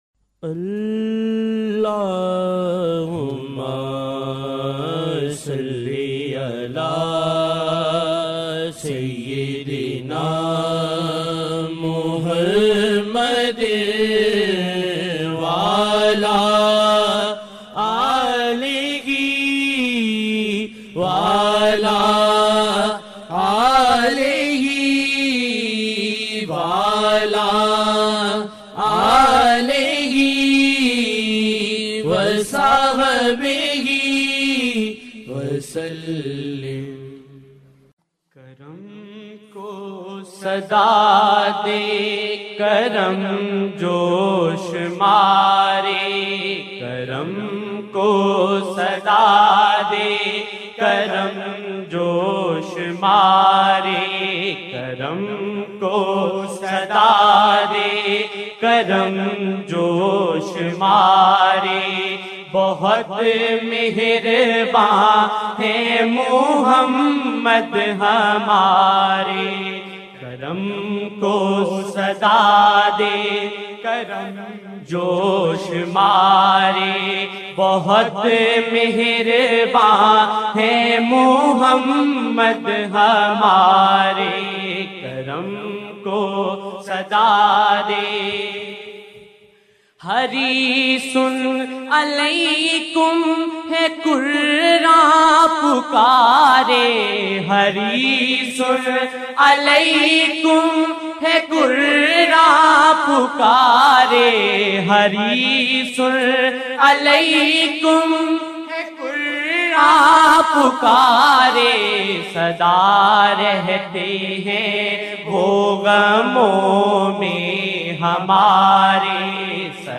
silsila ameenia | » Naat-e-Shareef